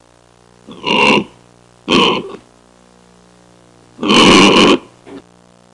Angry Pig Sound Effect
Download a high-quality angry pig sound effect.
angry-pig.mp3